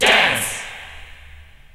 VOX SHORTS-1 0020.wav